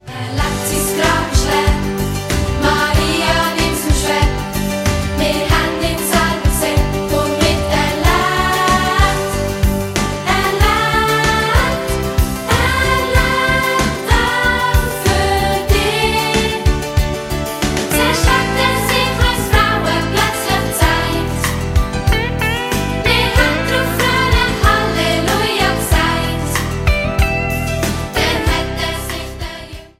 neue und alte Dialektsongs für Kinder